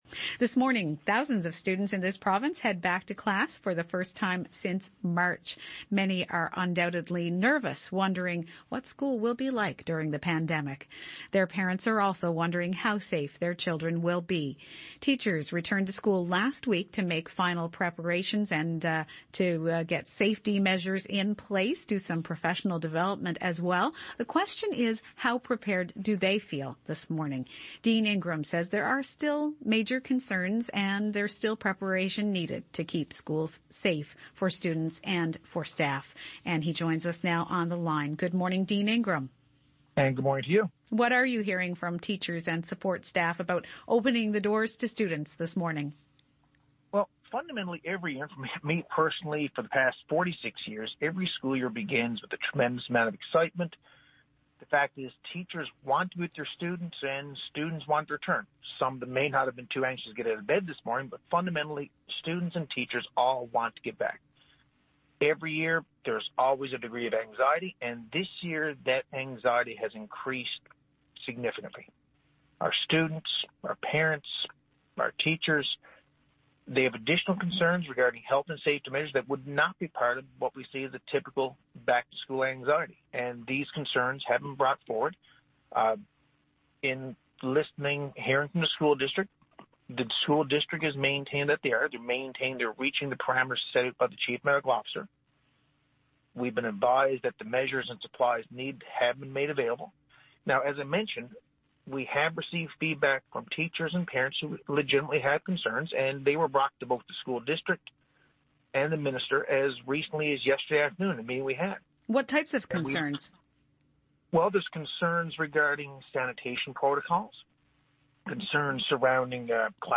Media Interview - CBC NL Morning - Sept 9, 2020